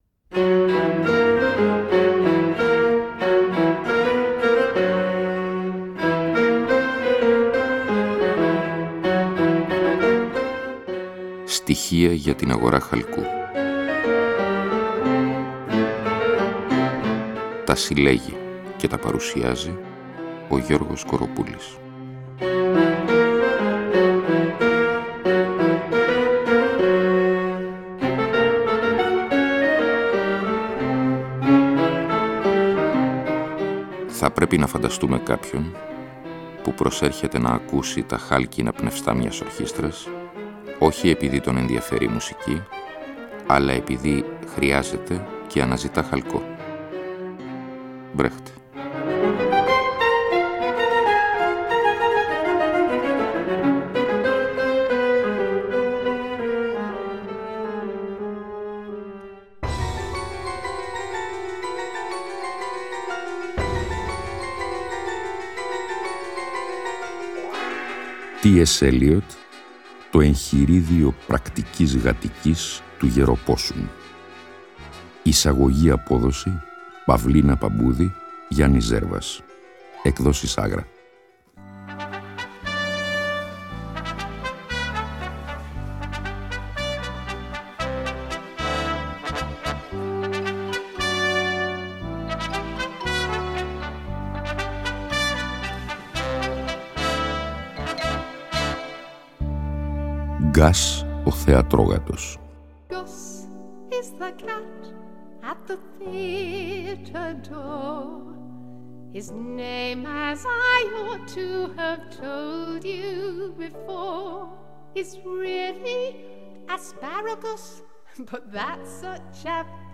Εκπομπή λόγου. Ακούγονται, ερμηνεύονται και συγκρίνονται με απροσδόκητους τρόπους κείμενα λογοτεχνίας, φιλοσοφίας, δοκίμια κ.λπ. Η διαπλοκή του λόγου και της μουσικής αποτελεί καθ εαυτήν σχόλιο, είναι συνεπώς ουσιώδης.